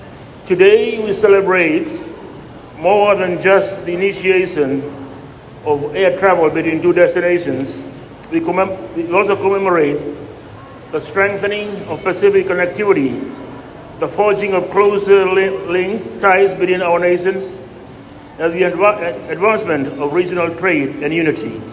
Speaking at the launching ceremony in Nadi, Gavoka emphasizes the importance of this new air route as a tangible manifestation of Fiji’s commitment to regional unity.